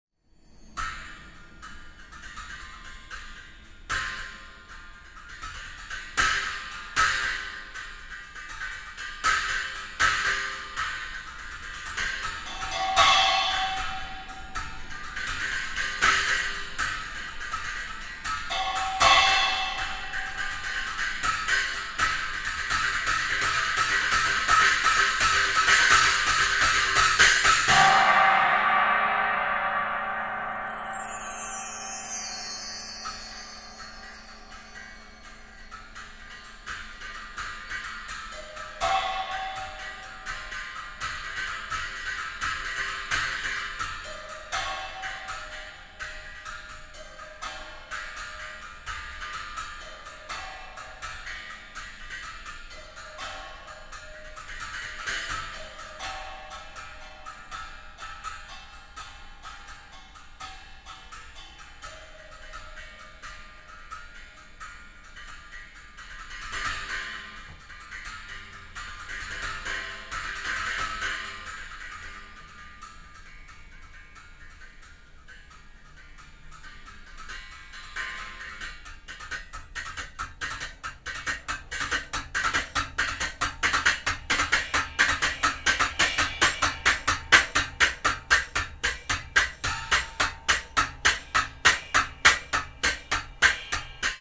ハーモニックなパーカッションが描き出す繊細でドラマティックな音像
drums, percussion